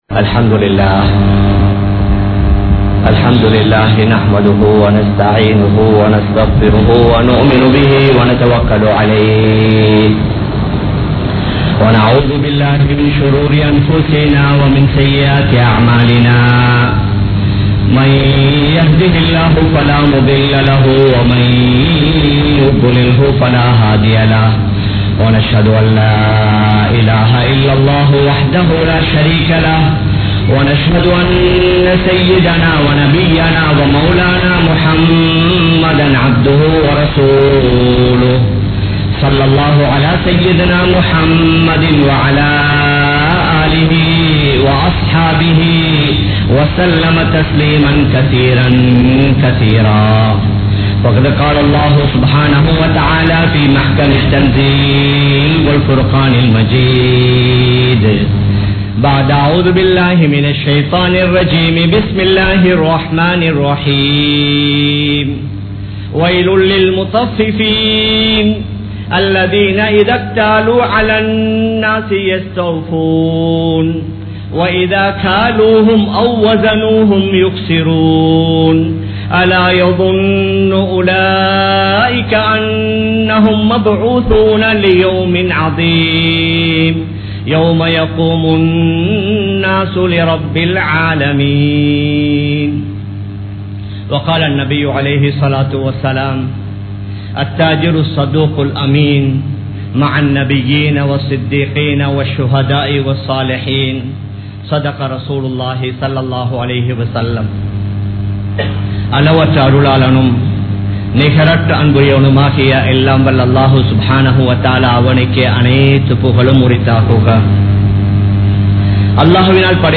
Mattravarhalai Eamattratheerhal (மற்றவர்களை ஏமாற்றாதீர்கள்) | Audio Bayans | All Ceylon Muslim Youth Community | Addalaichenai
Kollupitty Jumua Masjith